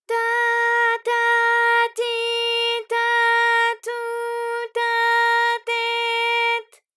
ALYS-DB-001-JPN - First Japanese UTAU vocal library of ALYS.
ta_ta_ti_ta_tu_ta_te_t.wav